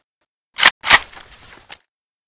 rpk74_unjam.ogg